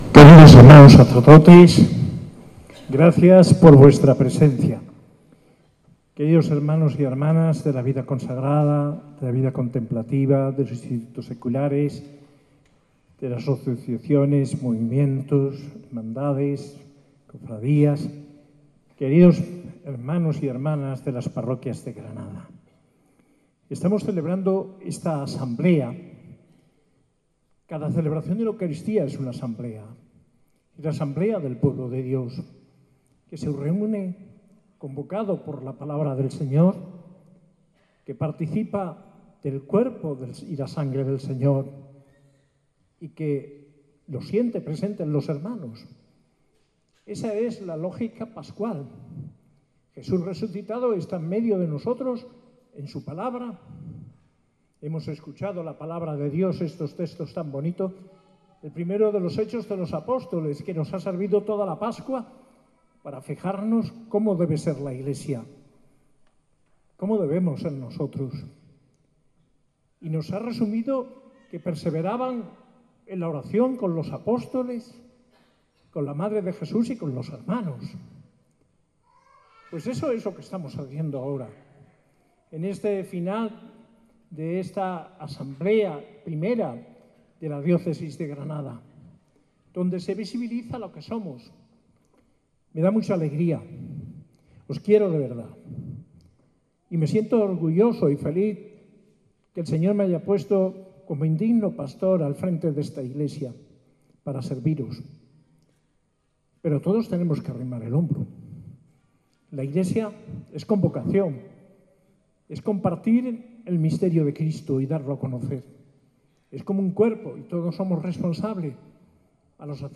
Homilía en la Eucaristía de la primera Asamblea diocesana, por el arzobispo Mons. José María Gil Tamayo, el 14 de junio de 2025.